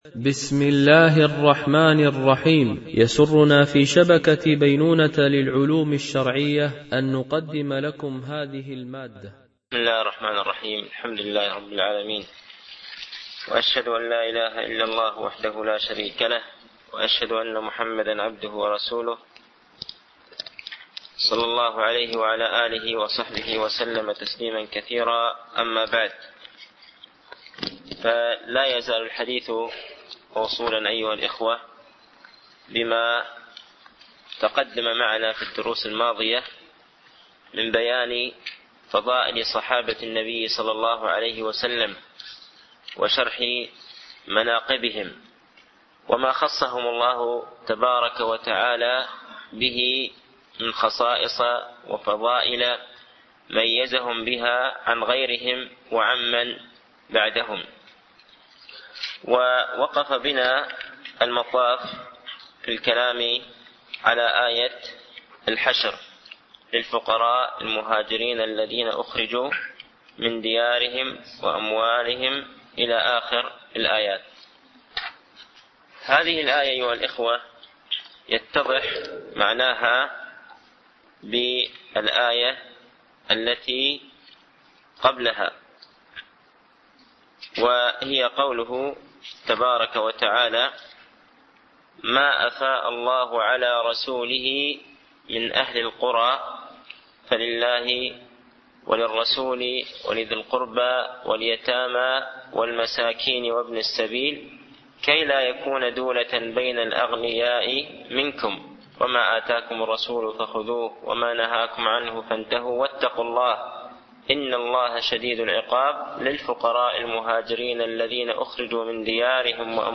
شرح أعلام السنة المنشورة ـ الدرس 138( ما الواجب التزامه في أصحاب رسول الله صلى الله عليه وسلم وأهل بيته ؟ )